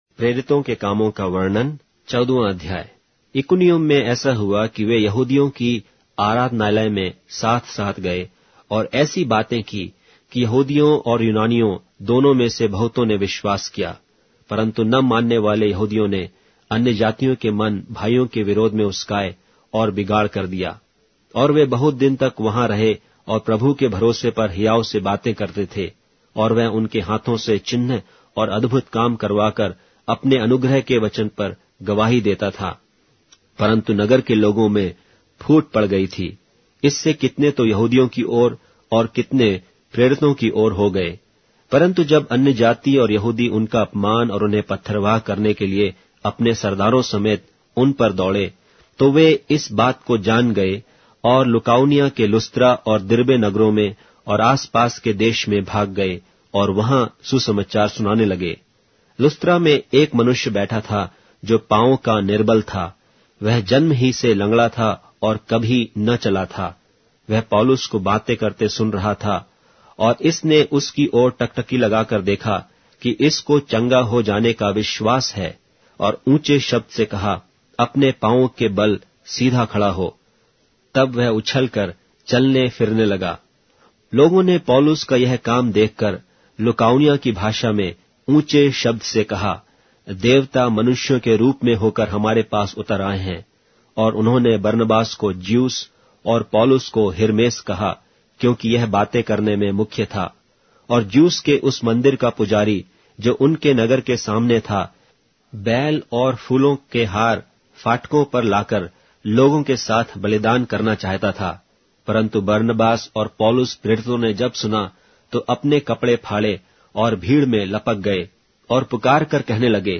Hindi Audio Bible - Acts 3 in Orv bible version